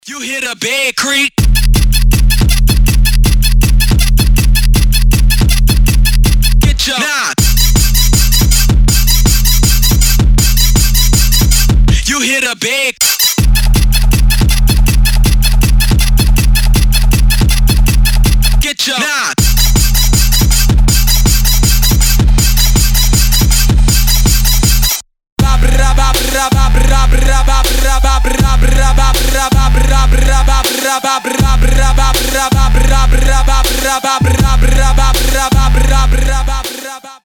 • Качество: 320, Stereo
веселые
Trap
быстрые
Сирена
moombahcore